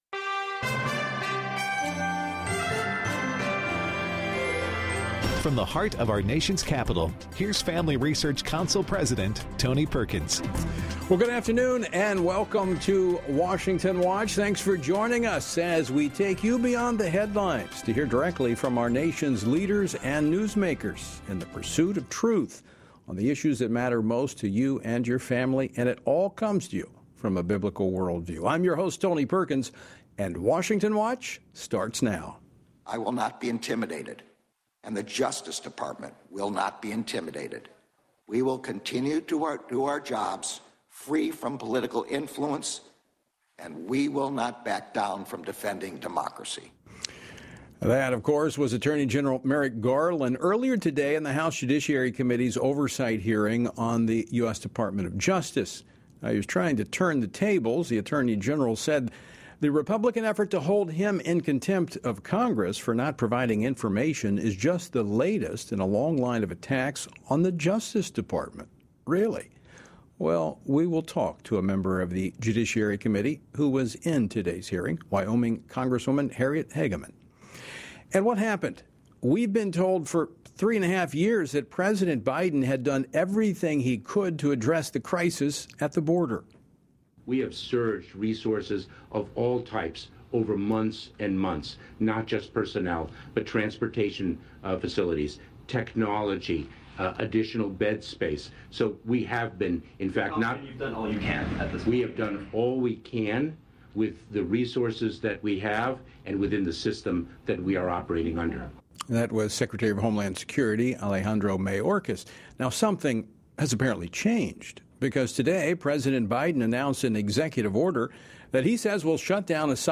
Show Notes On today’s program: Harriet Hageman , U.S. Representative for Wyoming, provides analysis of Attorney General Merrick Garland’s testimony before the House Judiciary Committee, where he faced criticism regarding the Biden administration’s two-tiered system of justice. Josh Brecheen , U.S. Representative for Oklahoma’s 2 nd District, reacts to President Biden’s executive order on U.S. asylum policy, which will attempt to address the border crises the Biden administration created. Dr. Andy Harris , U.S. Representative for the 1st District of Maryland, explains how upcoming legislation from Senate Democrats demonstrates the Left’s radical abortion agenda reflected at every level of government.